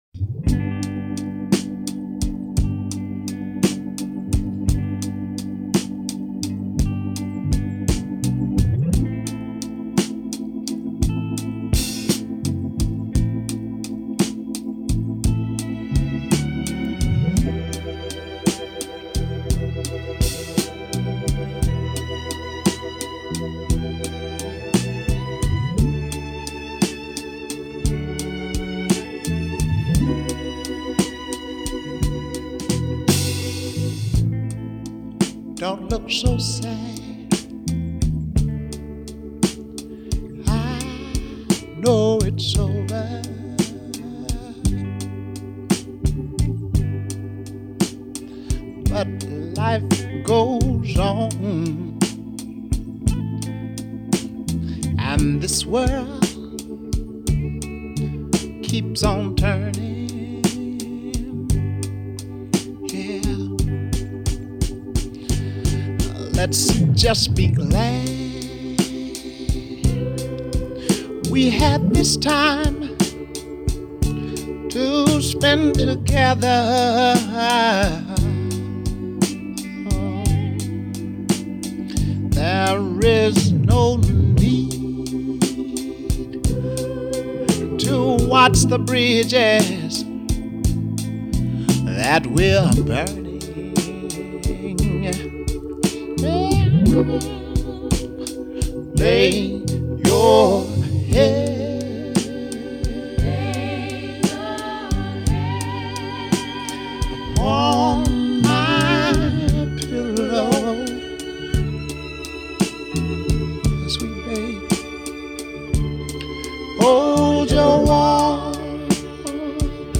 slow waltz